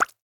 Minecraft Version Minecraft Version snapshot Latest Release | Latest Snapshot snapshot / assets / minecraft / sounds / block / bubble_column / bubble2.ogg Compare With Compare With Latest Release | Latest Snapshot
bubble2.ogg